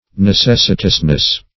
Ne*ces"si*tous*ness, n.